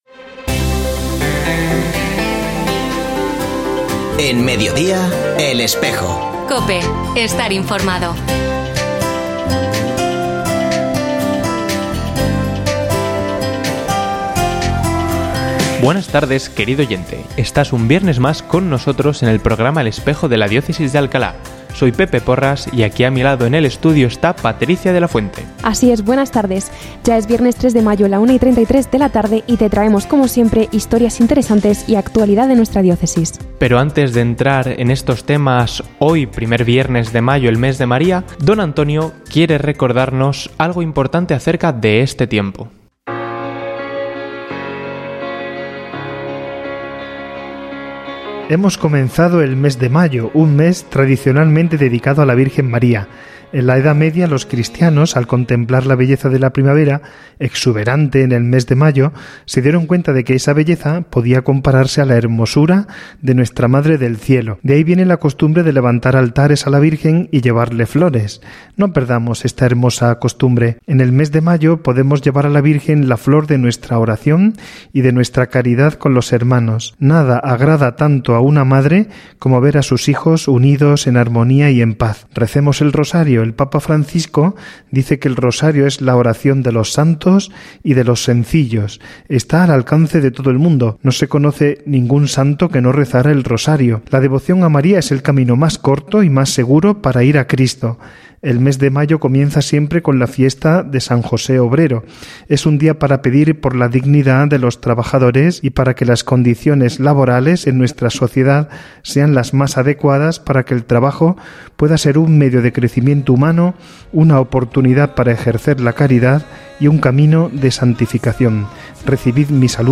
Escucha otras entrevistas de El Espejo de la Diócesis de Alcalá
Ofrecemos el audio del programa de El Espejo de la Diócesis de Alcalá emitido hoy, 3 de mayo de 2024, en radio COPE. Este espacio de información religiosa de nuestra diócesis puede escucharse en la frecuencia 92.0 FM, todos los viernes de 13.33 a 14 horas.